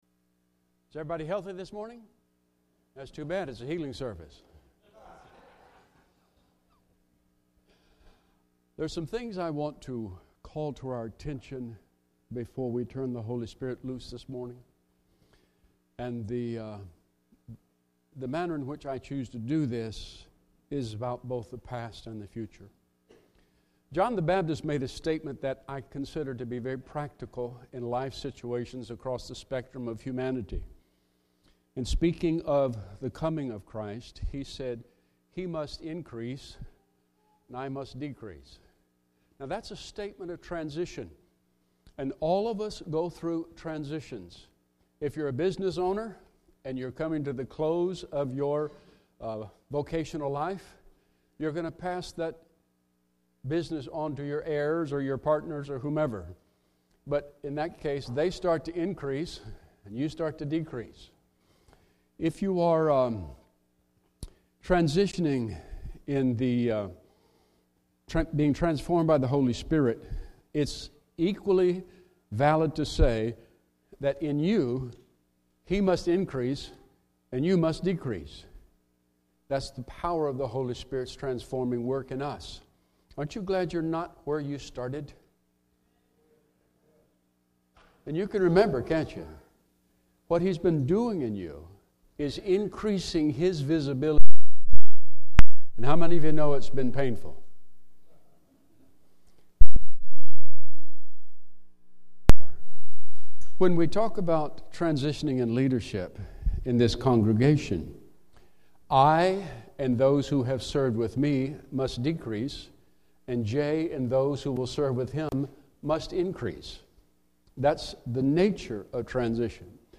Encounter Service: Transgenerational Values